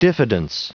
Prononciation du mot diffidence en anglais (fichier audio)
Prononciation du mot : diffidence